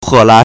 赫拉 Hèlā
he4la1.mp3